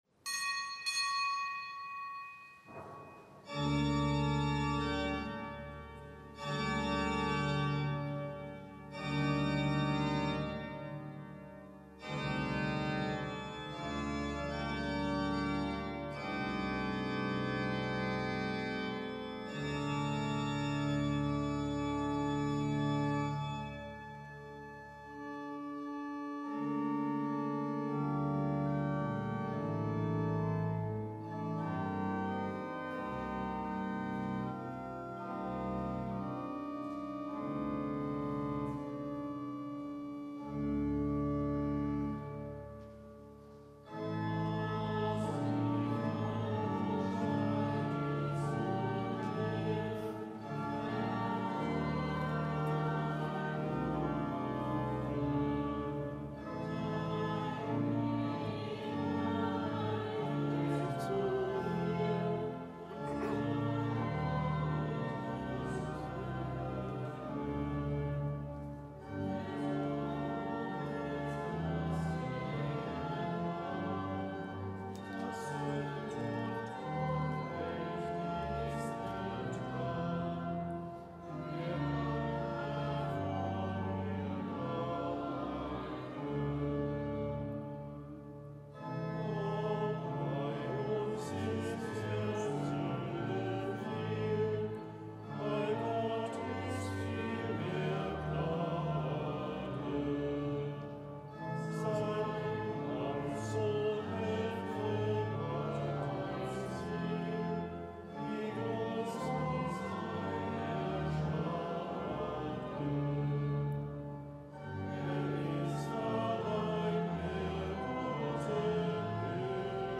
Kapitelsmesse aus dem Kölner Dom am Samstag der zweiten Fastenwoche, Zelebrant: Weihbischof Rolf Steinhäuser